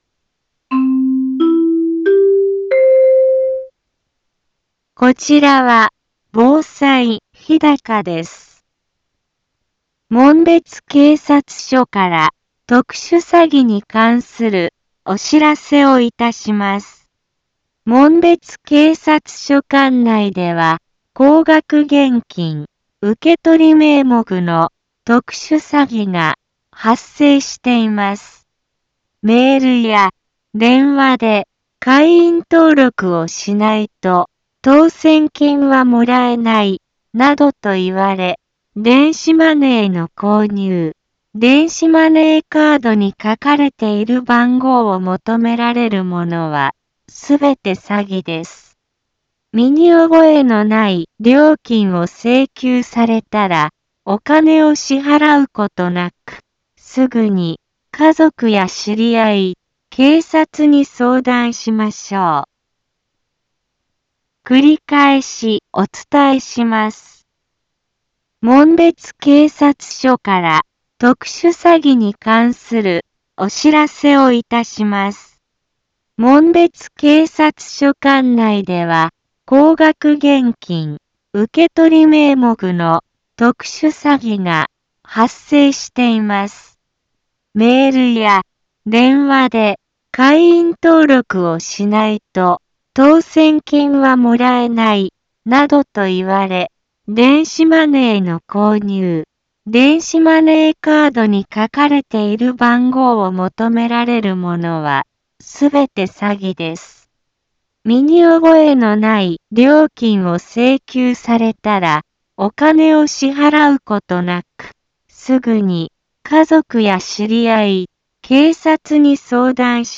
Back Home 一般放送情報 音声放送 再生 一般放送情報 登録日時：2021-11-29 15:04:44 タイトル：特殊詐欺に関するお知らせ インフォメーション：こちらは防災日高です。